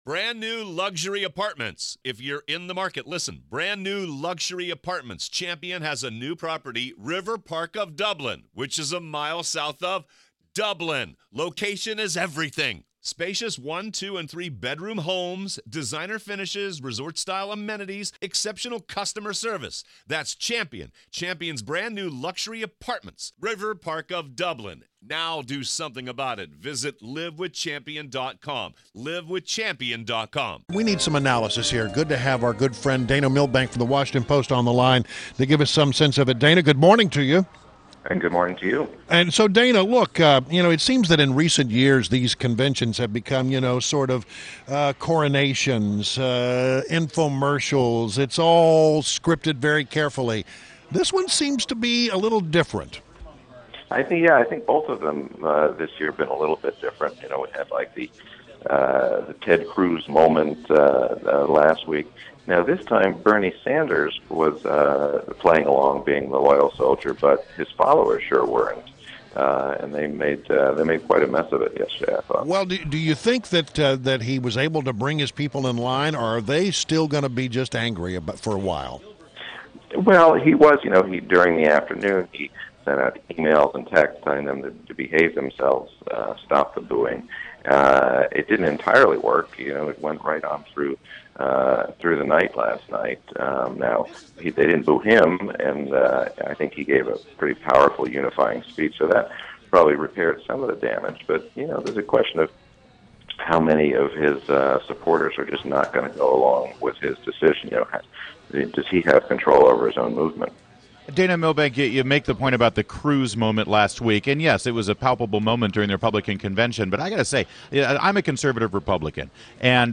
WMAL Interview - Dana Milbank - 07.26.16